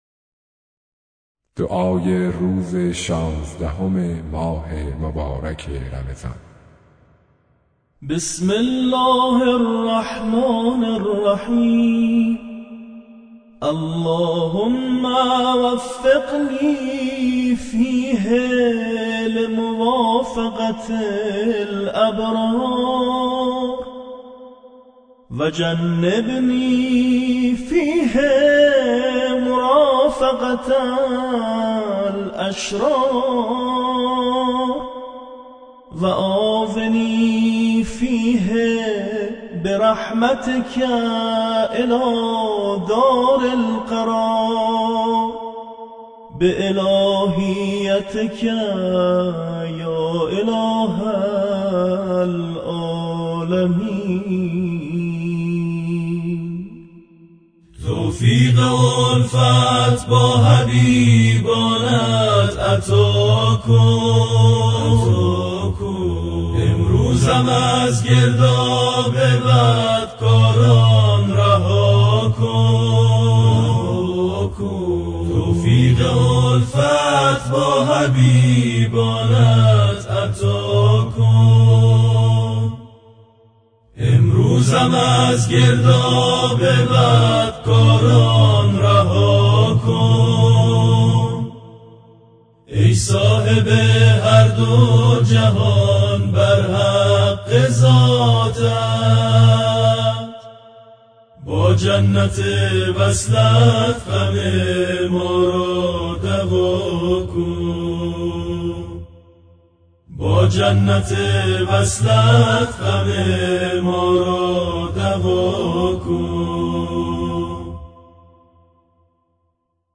برچسب ها: دعای روز شانزدهم ، ماه رمضان ، ادعیه ، مناجات